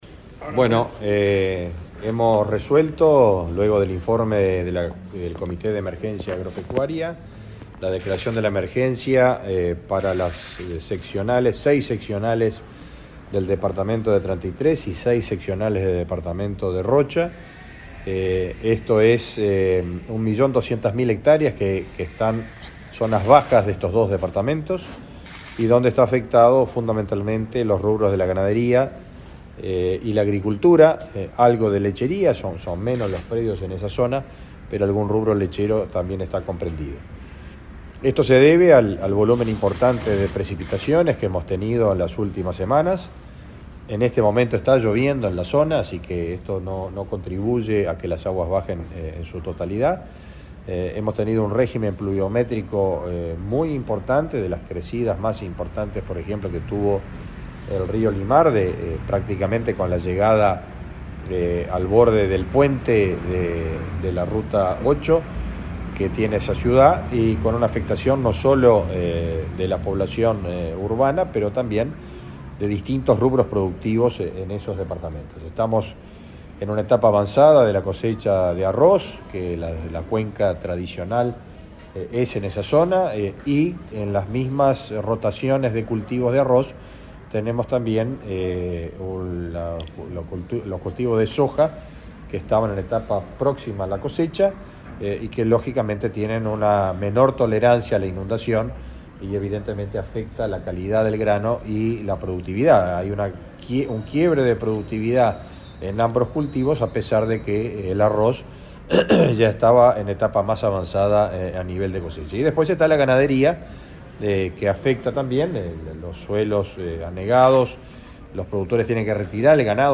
Declaraciones del ministro de Ganadería, Fernando Mattos
Este miércoles 22, el ministro de Ganadería, Fernando Mattos, informó a la prensa acerca de la declaración de emergencia agropecuaria por 120 días